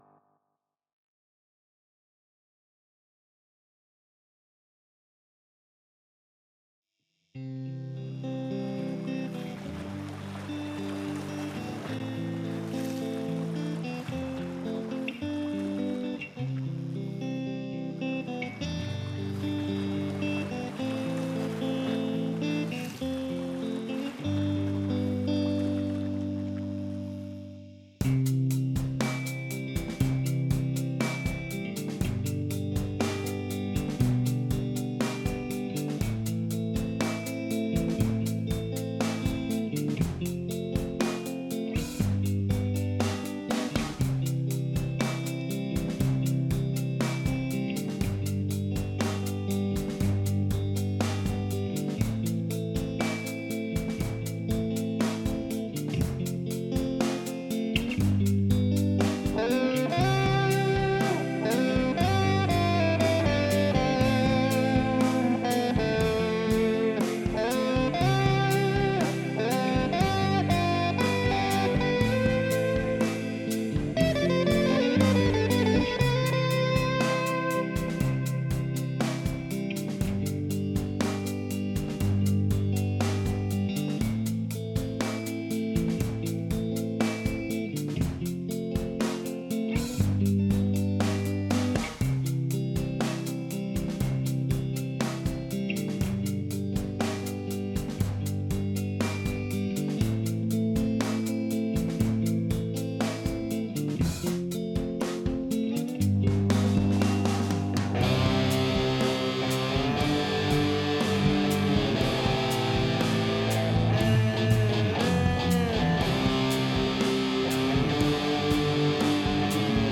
эээ... "округлости", чтоли гитарному звуку не хватает...
как будто прямо в линию писалось, без обработок...